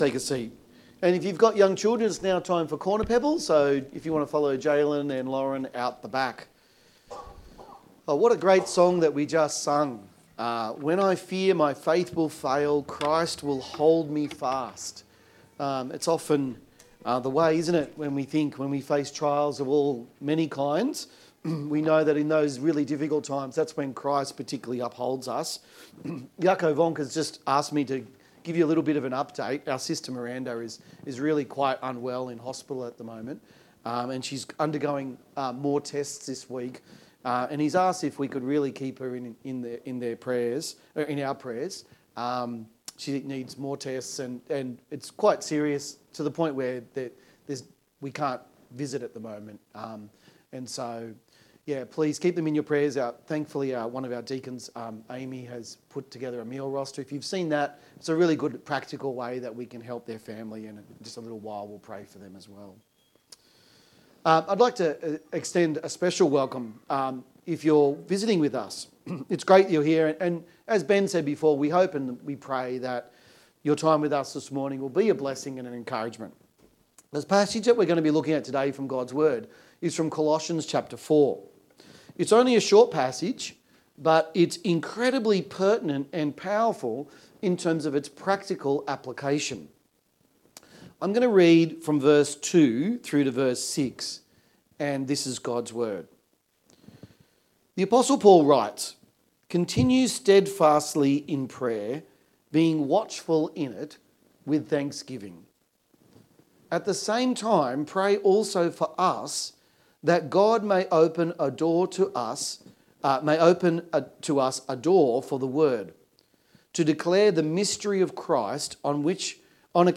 Colossians 4:2-6 Sermon